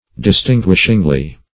Search Result for " distinguishingly" : The Collaborative International Dictionary of English v.0.48: Distinguishingly \Dis*tin"guish*ing*ly\, adv.
distinguishingly.mp3